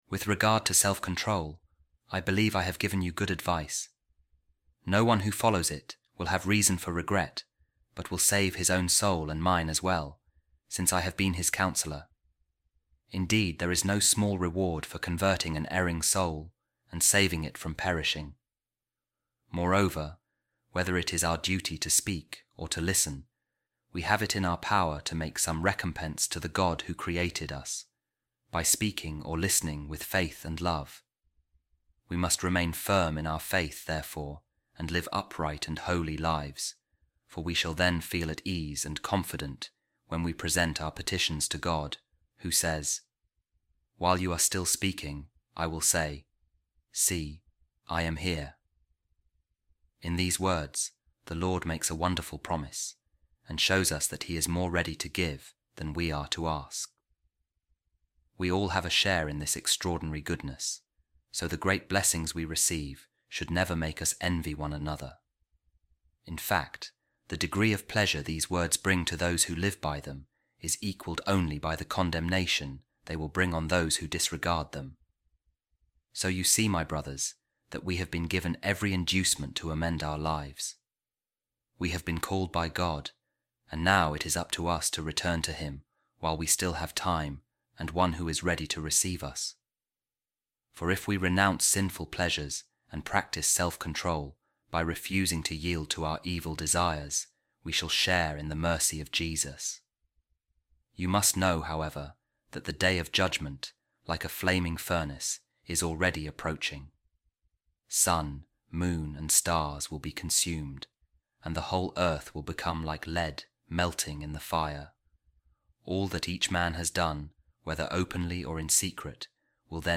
Office Of Readings | Week 32, Friday, Ordinary Time | A Reading From A Homily Of A Second-Century Author | Let Us Turn To God Who Has Called Us